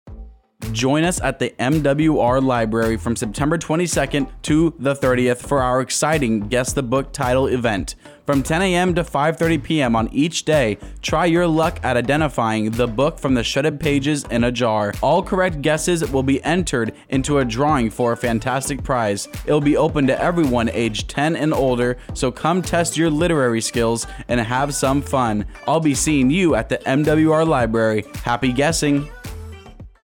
Thirty-second spot highlighting the Guess the Book Title to be aired on AFN Bahrain's morning and afternoon radio show.
Radio SpotAudio SpotMWR BahrainAFN Bahrain